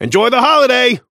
Shopkeeper voice line - Enjoy the holiday!